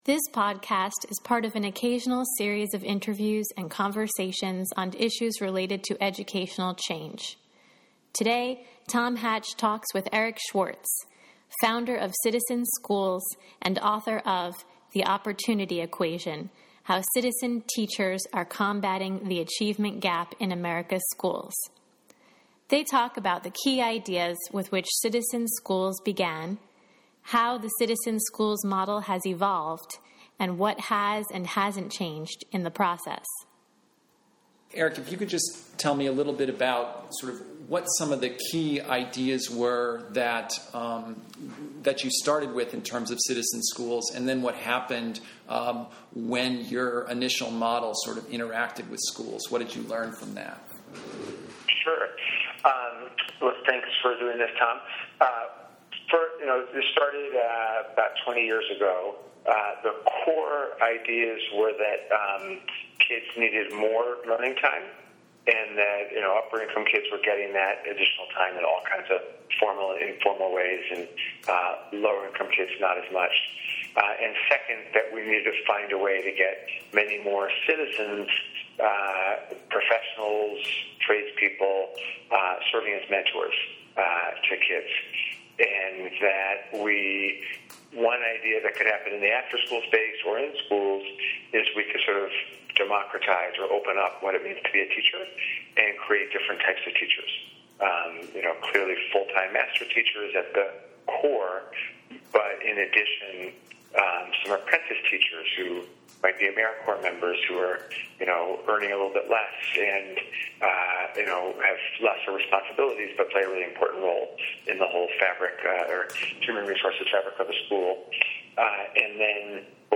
This podcast is part of an occasional series of interviews and conversations on issues related to educational change.